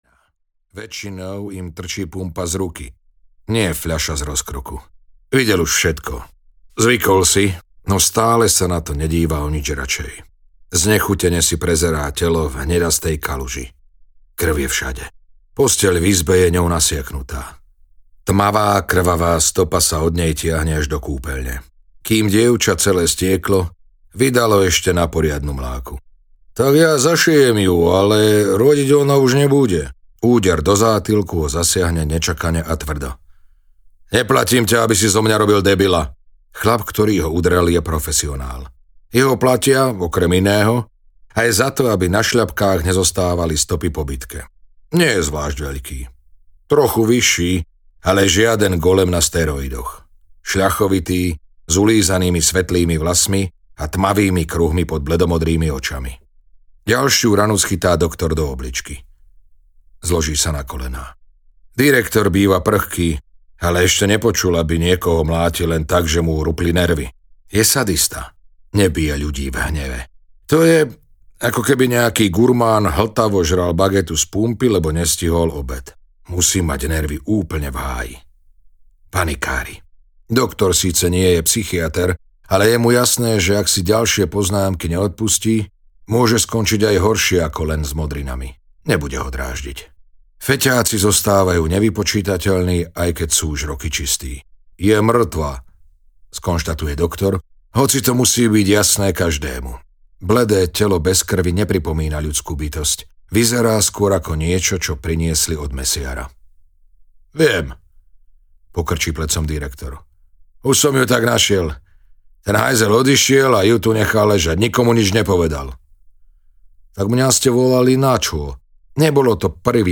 Sviňa audiokniha
Ukázka z knihy